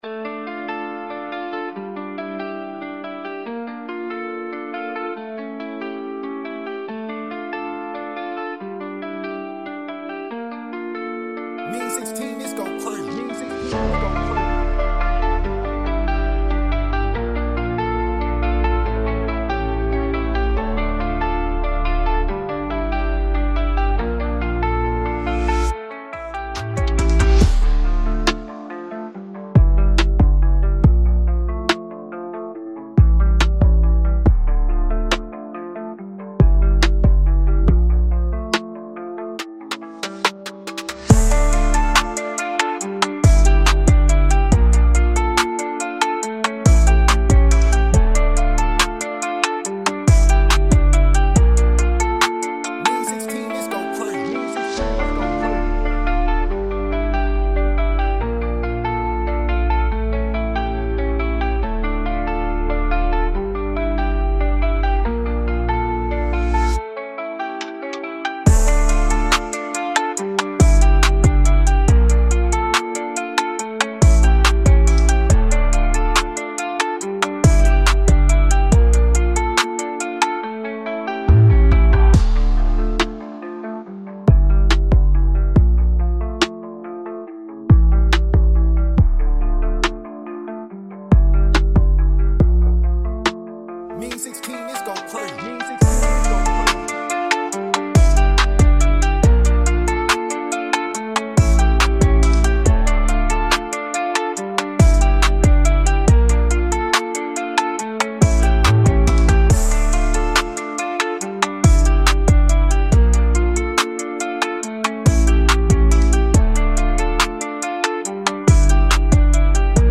MELODIC TRAP BEAT
D-Min 140-BPM